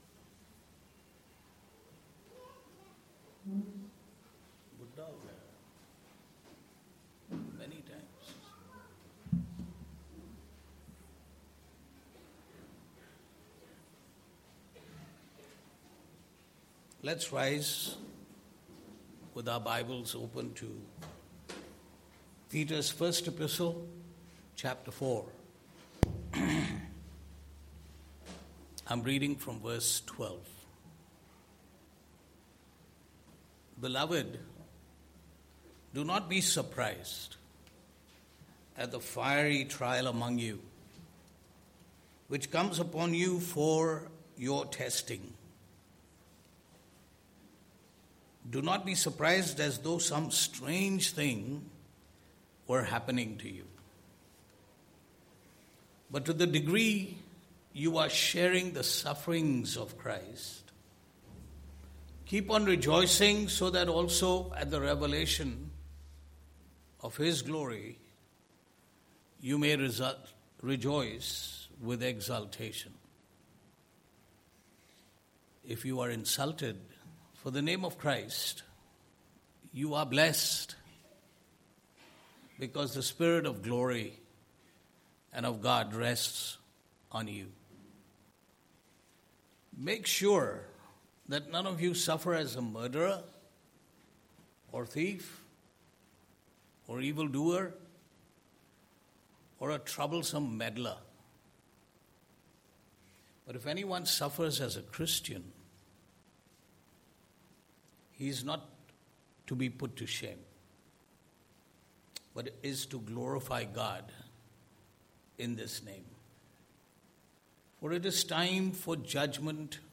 Passage: 1 Peter 4 : 12-19 Service Type: Sunday Morning